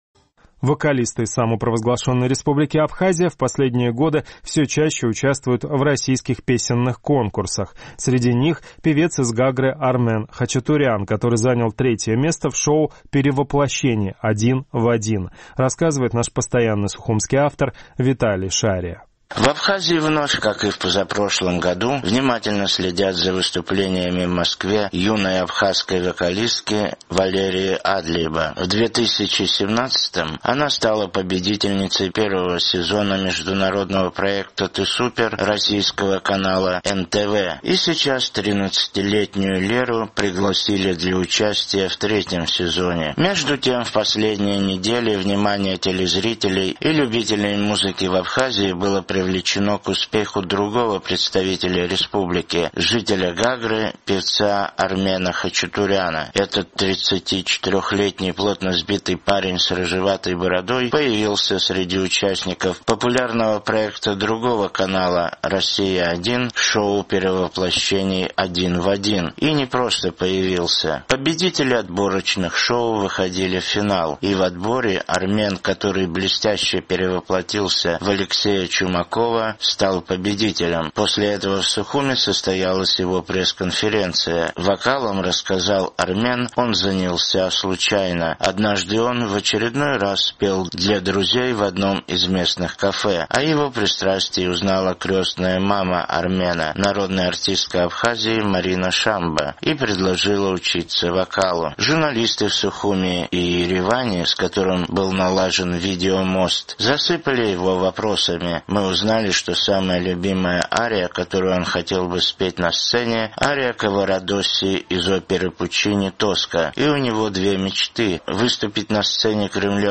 (Звучит песня «Летний дождь»).